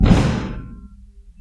hit hospital percussion sound effect free sound royalty free Memes